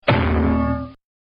Cartoon Bonk Sound Effect Free Download
Cartoon Bonk